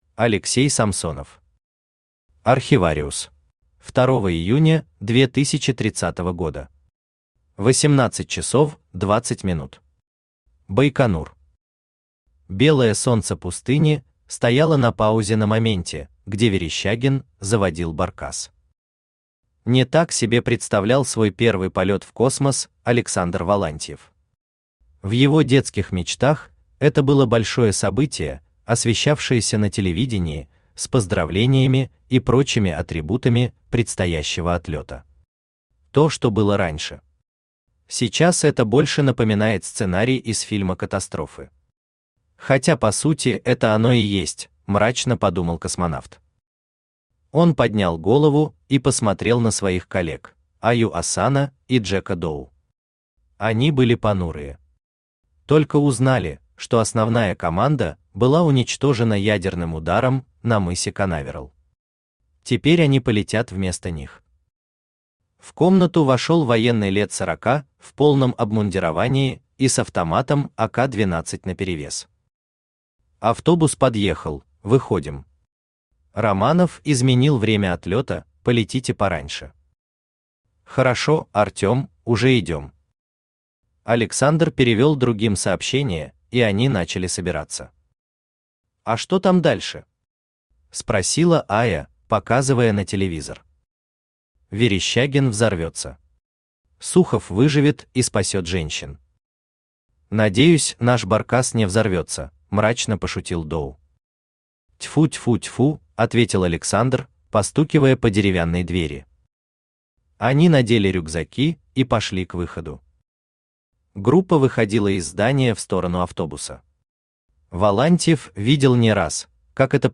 Aудиокнига Архивариус Автор Алексей Самсонов Читает аудиокнигу Авточтец ЛитРес.